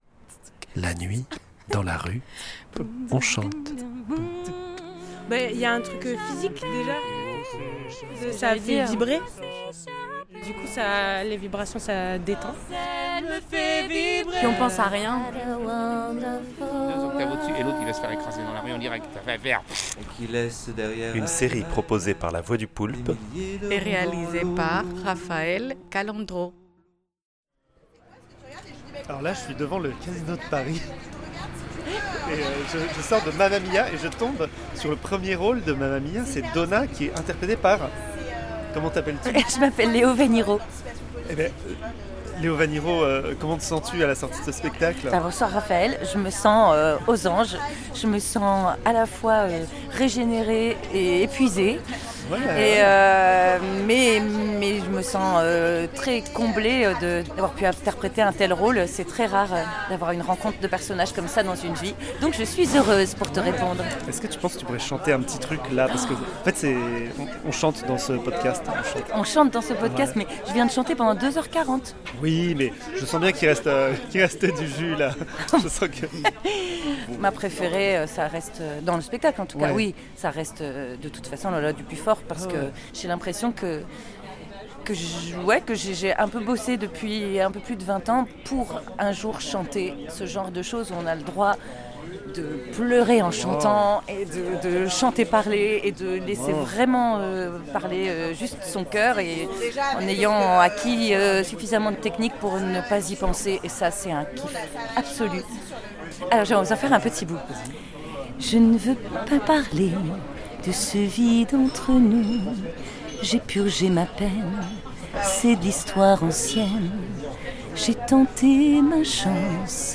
devant le Casino de Paris, après le spectacle Mamma mia | La voix du Poulpe